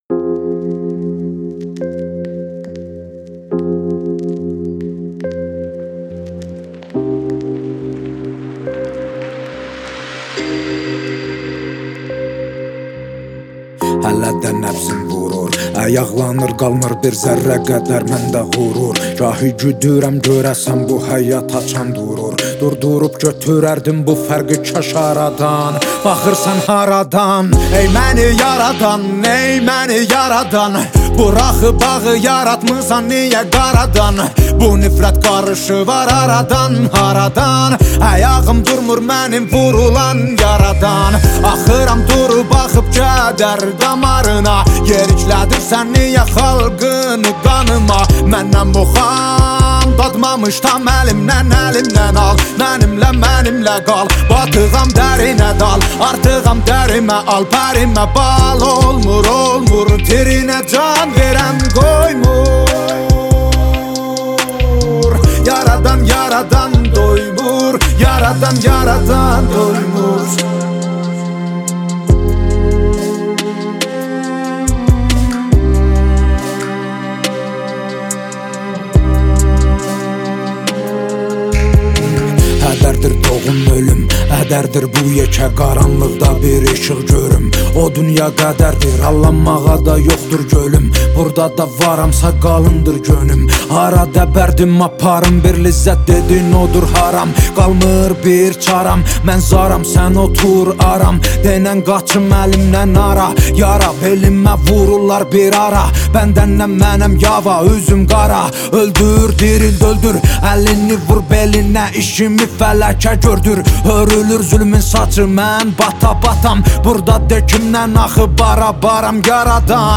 موزیک ترکی